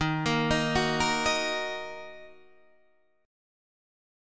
D#m chord